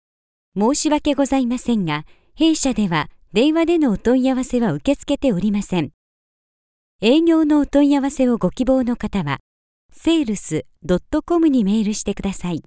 JP HO IVR 01 IVR/Phone systems Female Japanese